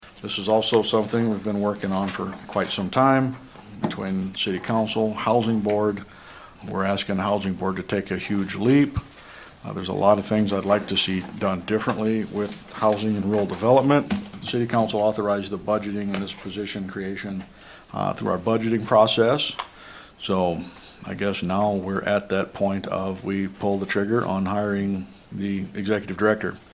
Also at Wednesday’s council meeting:
Mayor Cox talked about the creation of this position.